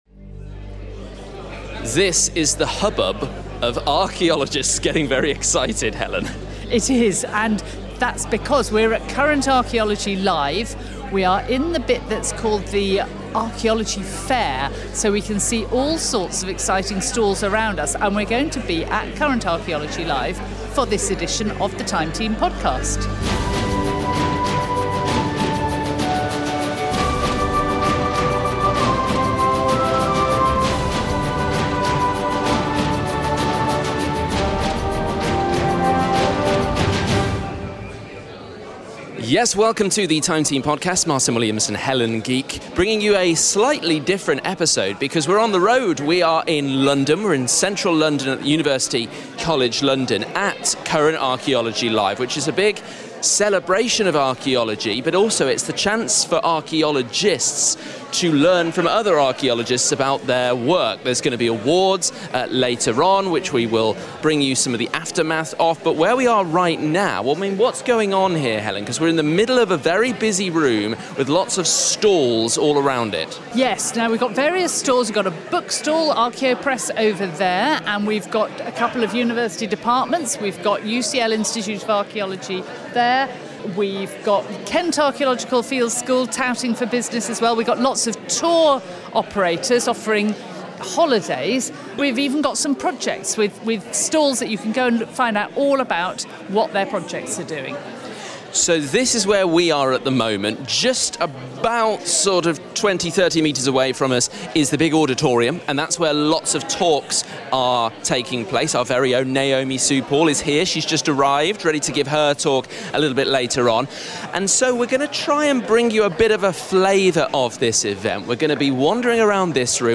We're at Current Archaeology Live at UCL in London. Guest speakers from the world of archaeology are presenting to a packed audience.